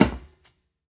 StepMetal5.ogg